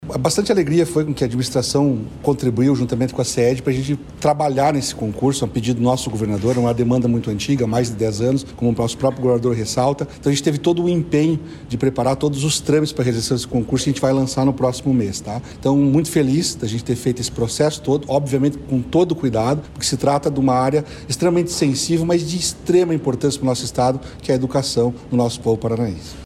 Sonora do secretário da Administração e Previdência, Elisandro Pires Frigo, sobre a abertura de concurso para professores na rede estadual